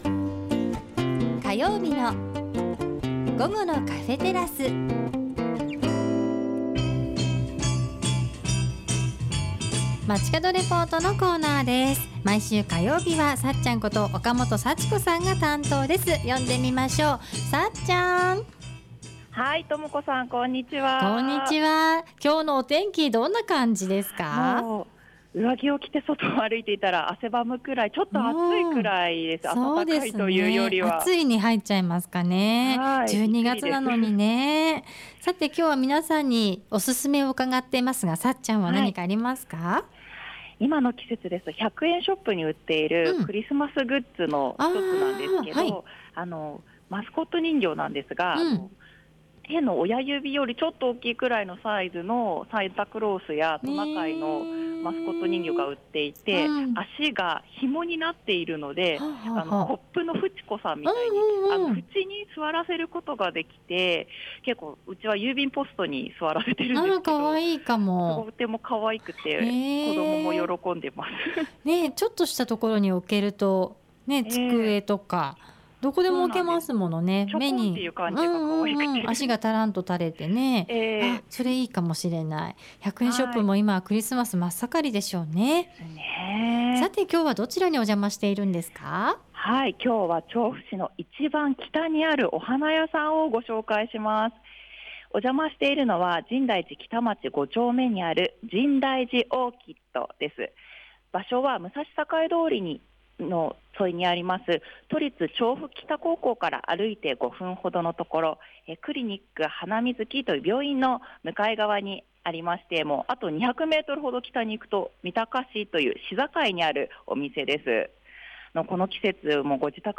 中継では調布市の一番北にあるお花屋さんをご紹介しました♪ お邪魔したのは深大寺北町５丁目にある「深大寺オーキット」。
見事な胡蝶蘭♪ 洋ラン一筋でお店は２7年目を迎えられ、 お店の中には200鉢以上の洋ランがずらり‼ お店の中は程よい暖かさの温室で、白、薄紫色、黄色、クリーム色、ピンクなど、 ３６０度華やかで可憐な大きな花をつけた洋ランに囲まれながらの中継でした。